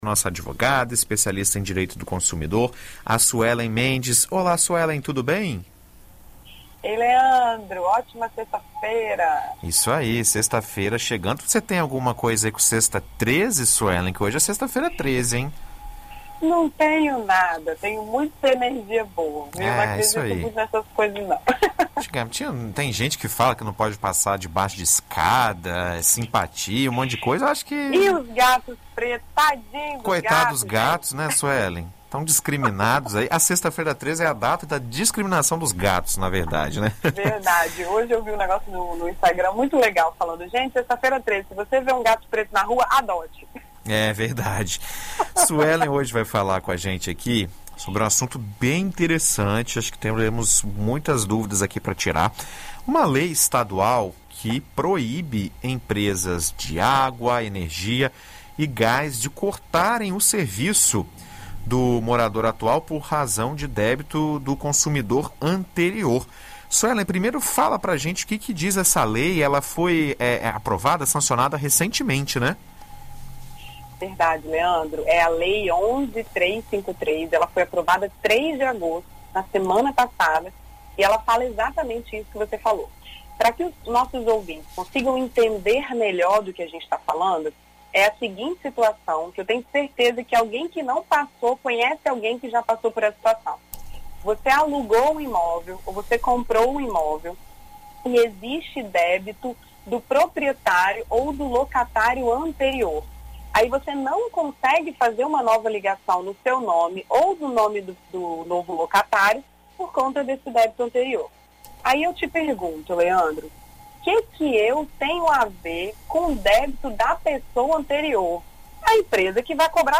Na BandNews FM, advogada explica nova lei estadual que proíbe que essas empresas interrompam o serviço do morador atual pelo débito do consumidor anterior